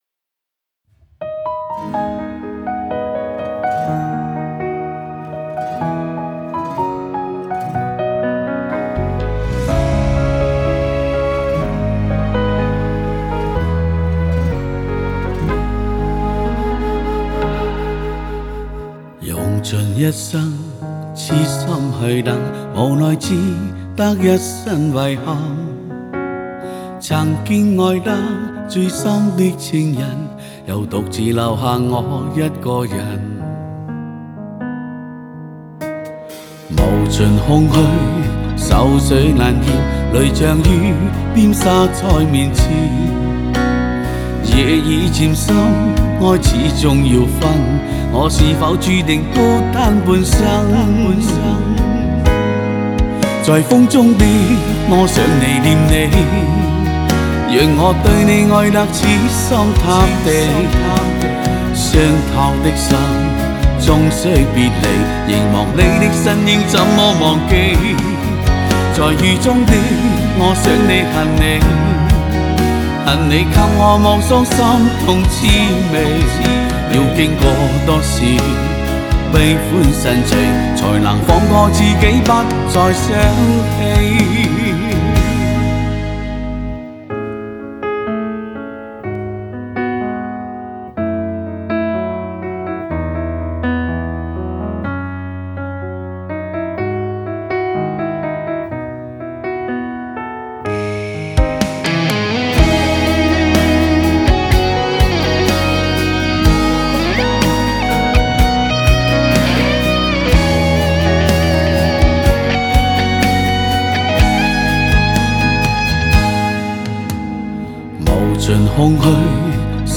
Ps：在线试听为压缩音质节选，体验无损音质请下载完整版
粤语金曲